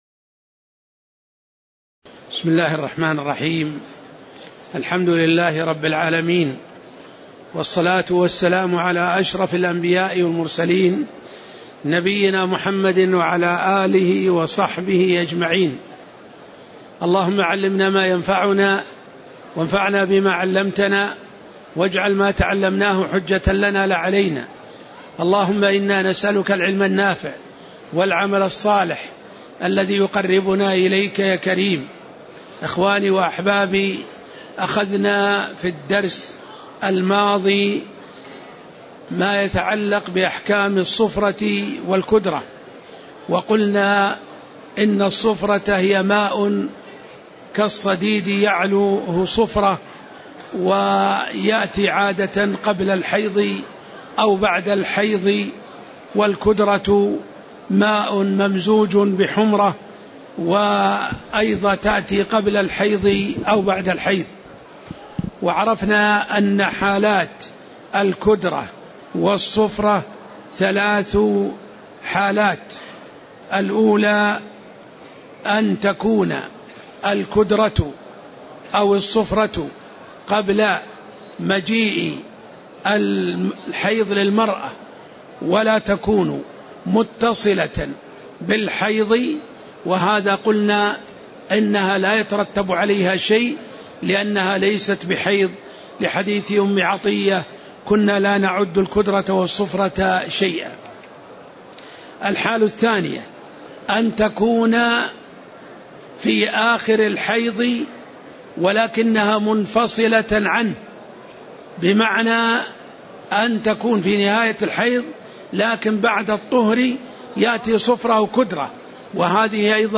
تاريخ النشر ٨ جمادى الآخرة ١٤٣٧ هـ المكان: المسجد النبوي الشيخ